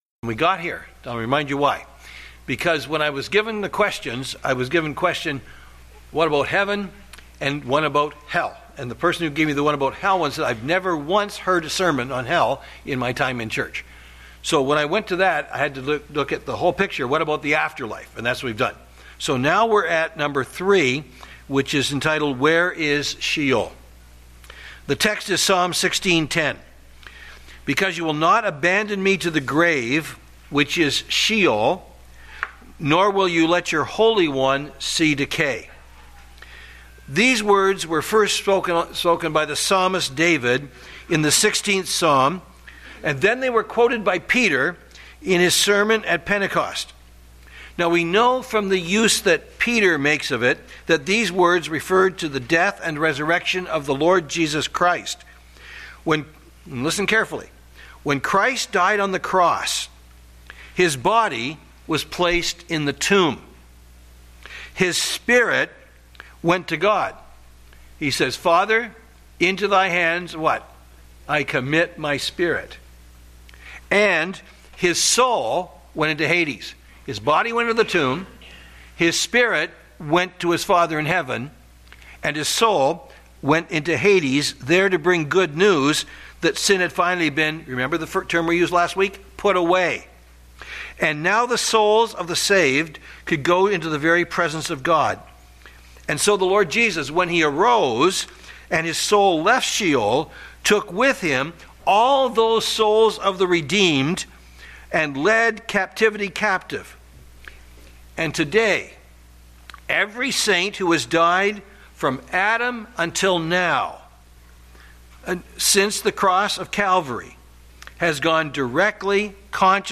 Sermons | Bethel Bible Church